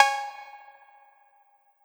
Tron Cat Cowbell.wav